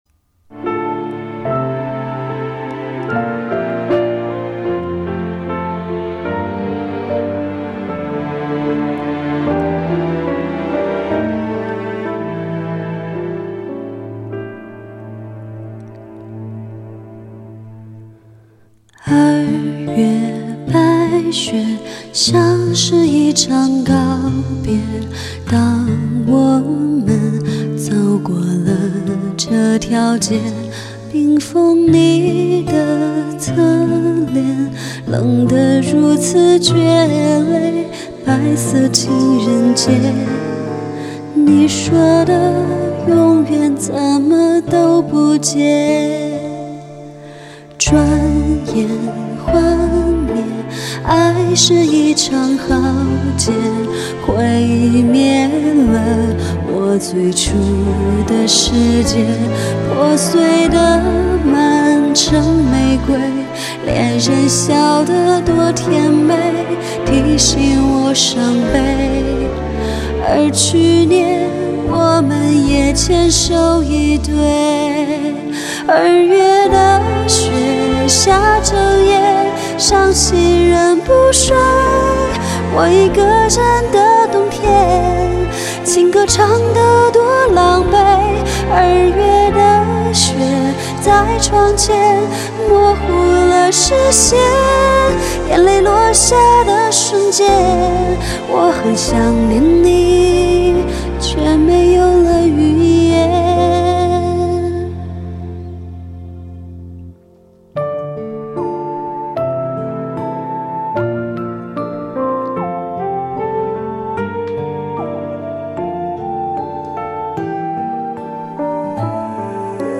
和朋友一起参加了可口可乐的翻唱比赛，希望大家多多支持，喜欢的话在网站上给个好评！